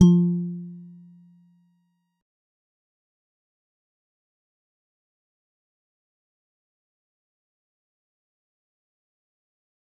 G_Musicbox-F3-mf.wav